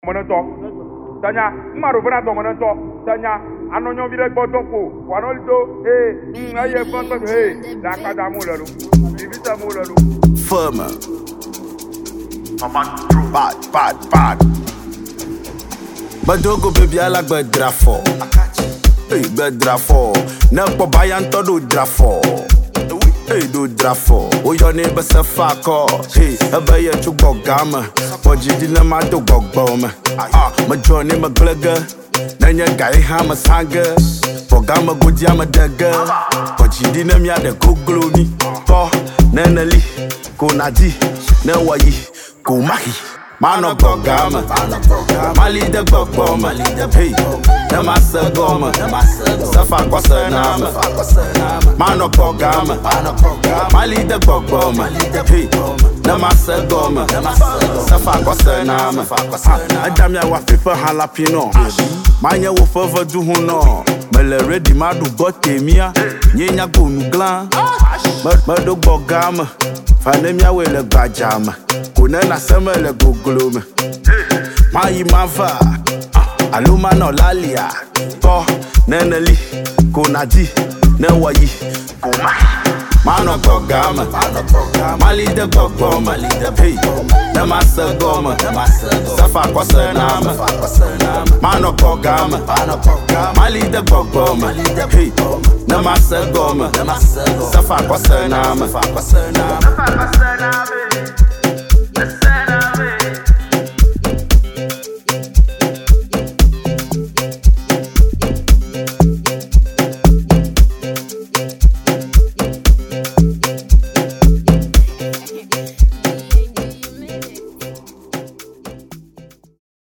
Known for his unique blend of Afro-fusion and modern rhythms
energetic, dance-ready beats